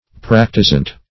Practisant \Prac"ti*sant\, n. An agent or confederate in treachery.
practisant.mp3